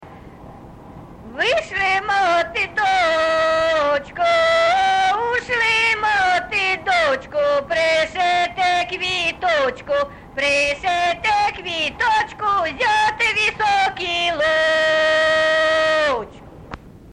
ЖанрВесільні